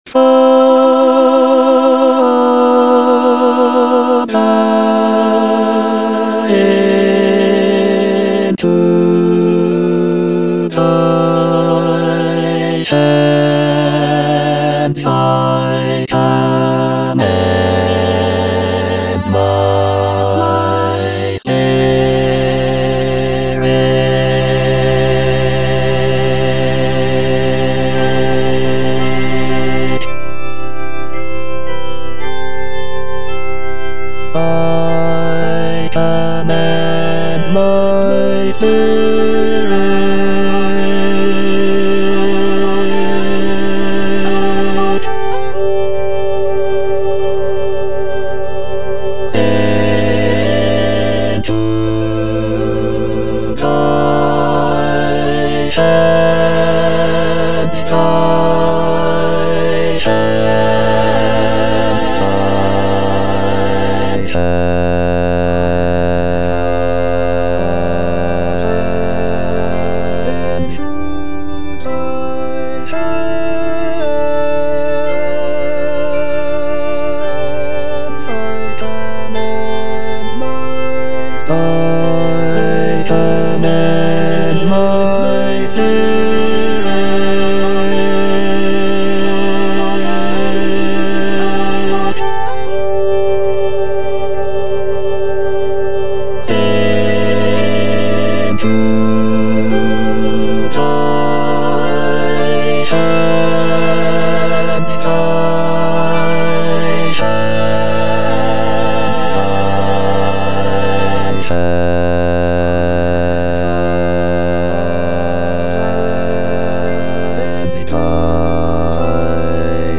bass I) (Emphasised voice and other voices) Ads stop